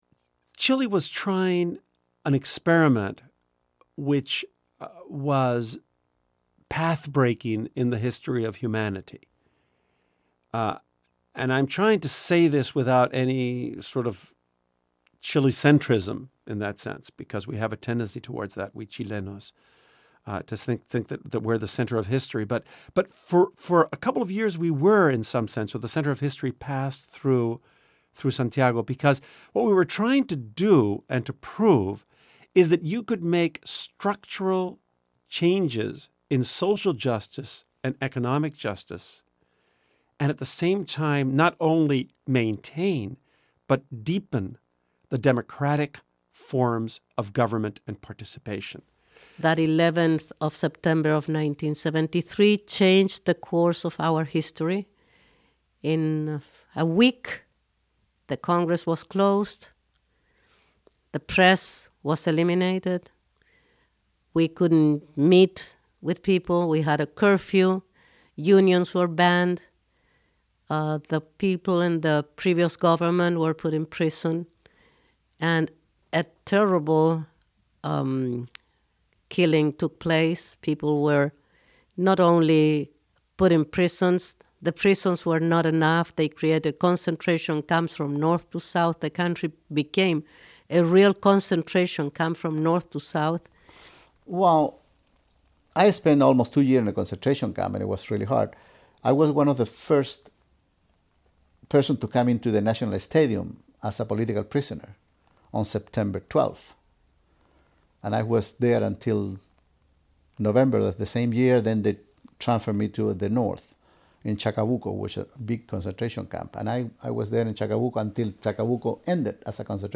This CD documentary Features Isabel Allende, Ariel Dorfman, and others along with historic sound recordings and music.